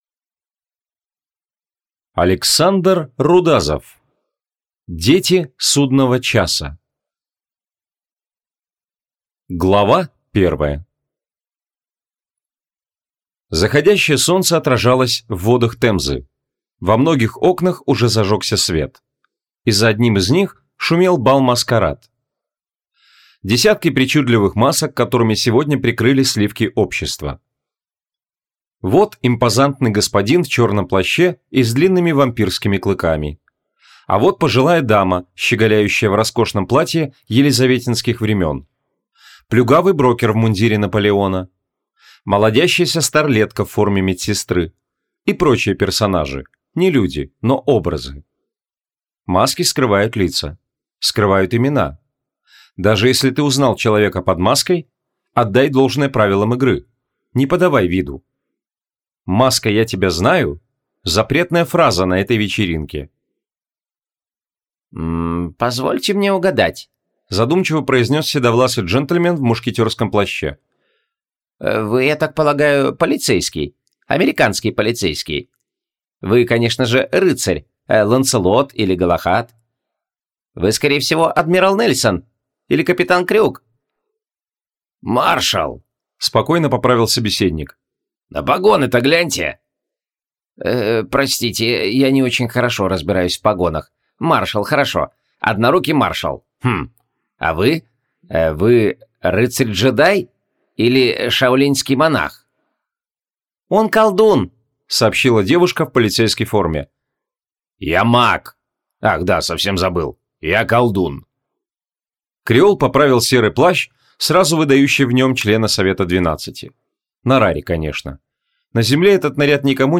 Аудиокнига Дети Судного Часа | Библиотека аудиокниг